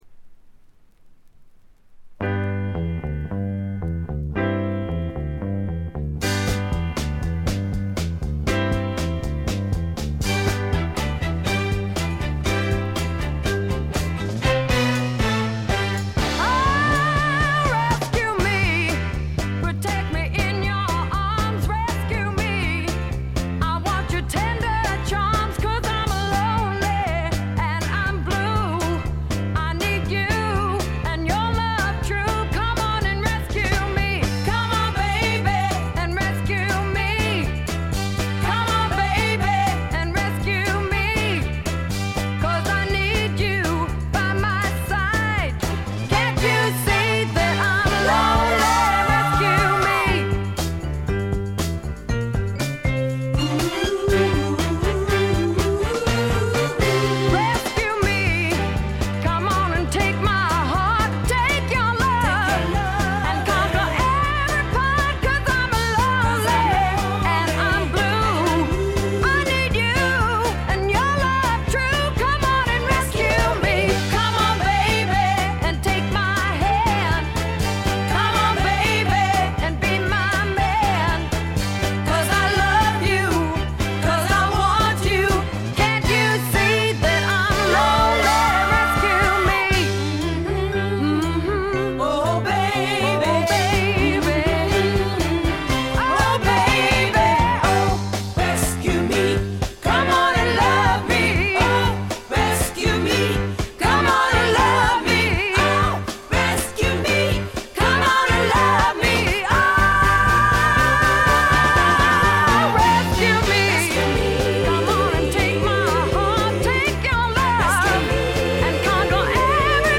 軽微なチリプチ少々。
スワンプ系女性ヴォーカル・アルバムの快作です。
試聴曲は現品からの取り込み音源です。